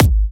006_Kick1.wav